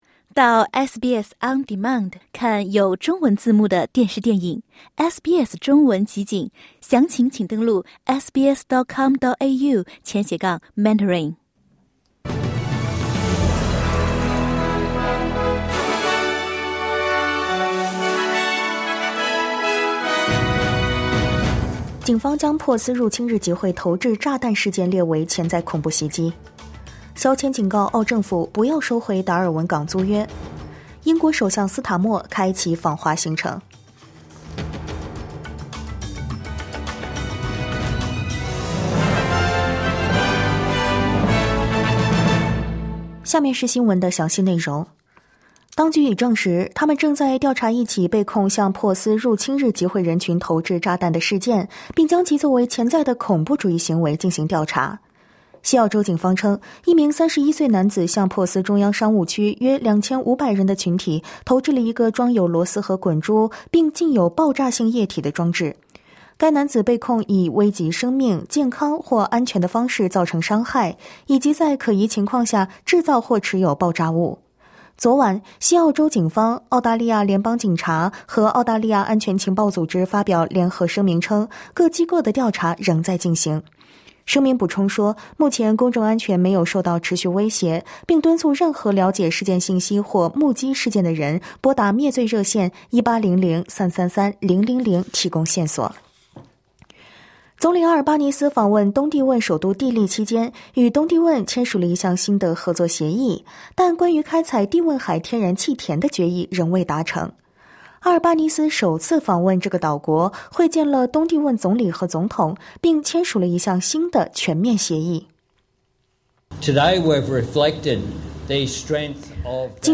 【SBS早新闻】肖千警告澳政府 不要收回达尔文港租约